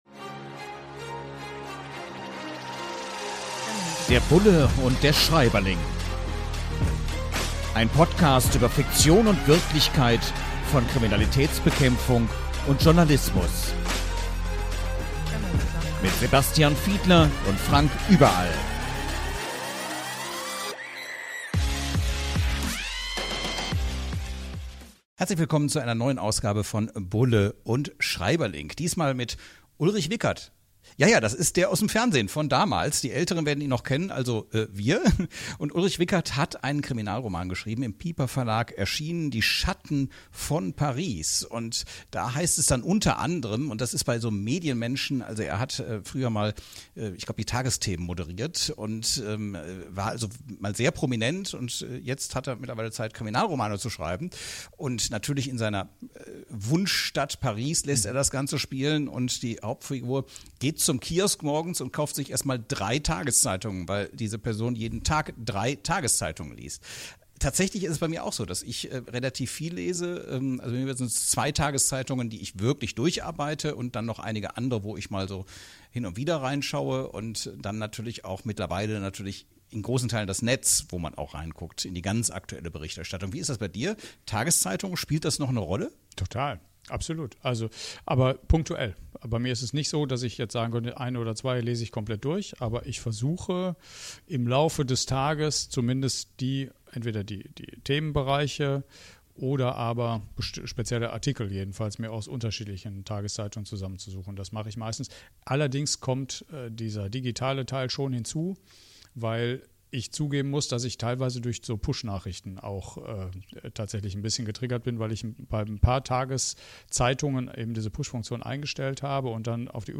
In launiger Atmosphäre tauschen sich die Beiden anhand von Aspekten aus, die in dem Buch beschrieben werden.